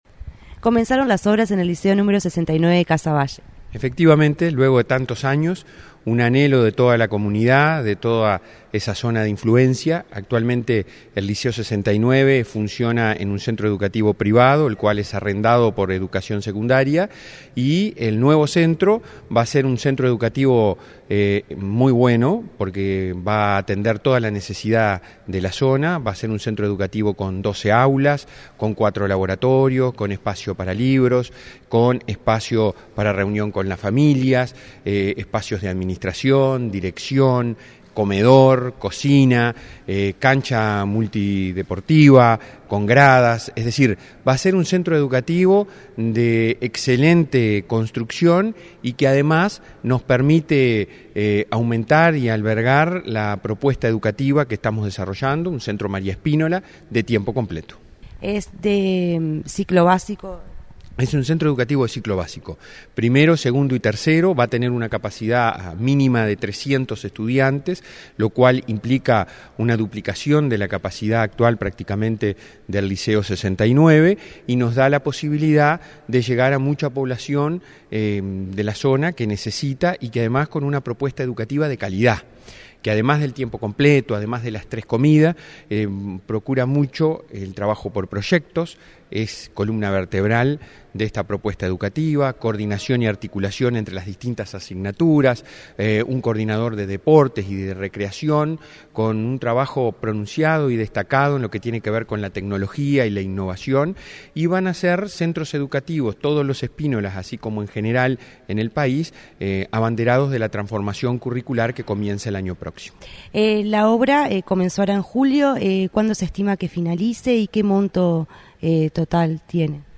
Entrevista al presidente del Codicen, Robert Silva